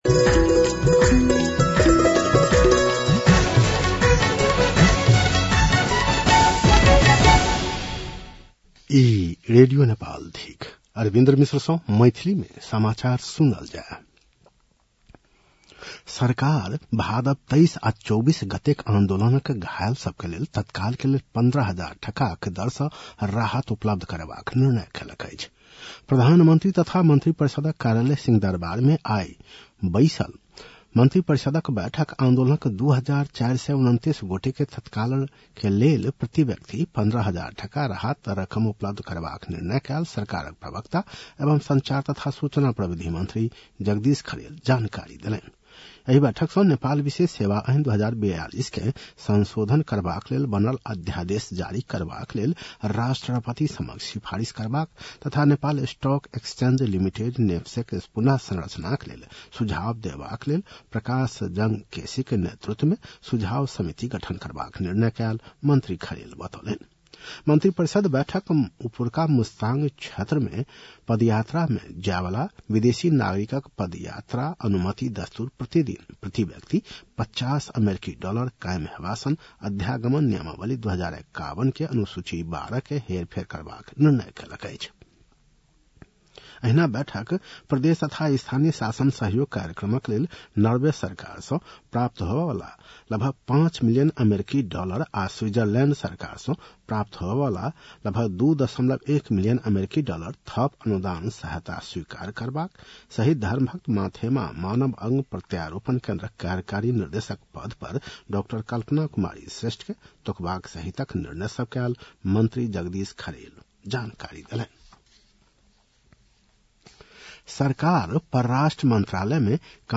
मैथिली भाषामा समाचार : २ मंसिर , २०८२